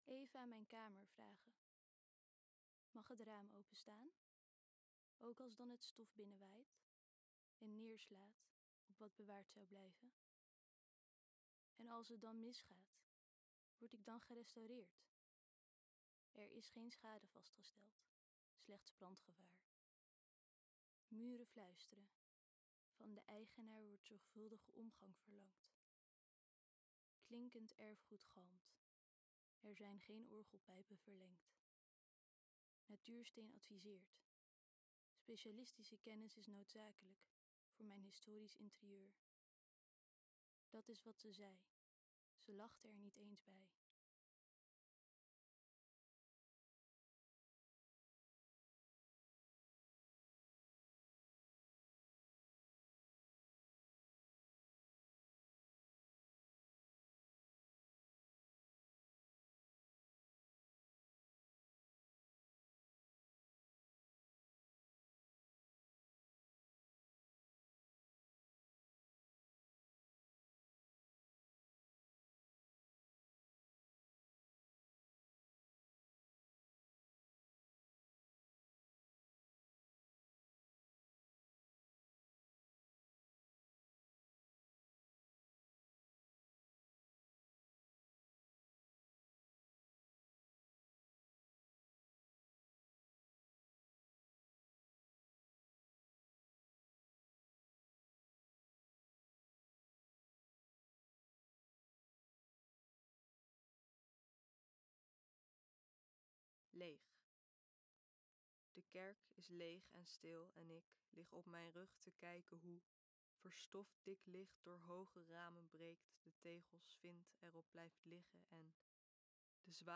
Declaimed poems sounded from five speakers on tripods (duration 00:37:26).
A group of young writers delved into legal documents and formal correspondence related to the installation of a red window in the Holy Sepulchre Chapel. The poems were read aloud in this sound work by the authors themselves.